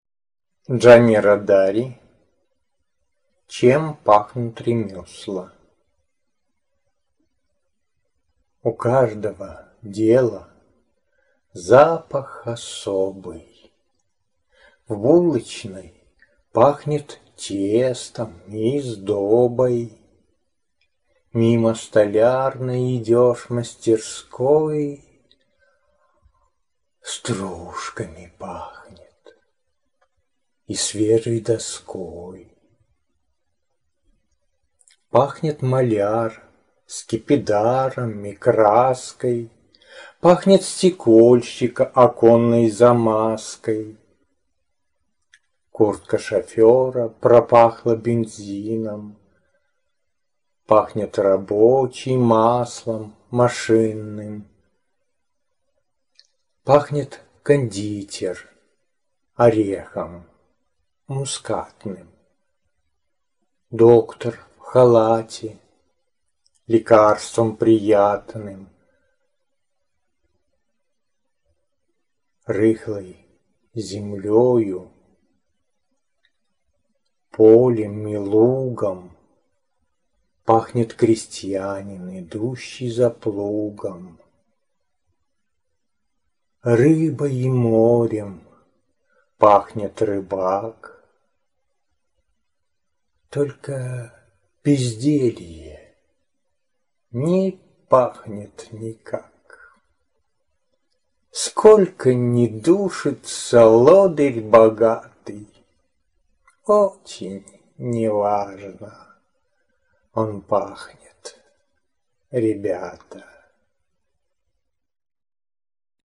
t звучащие стихи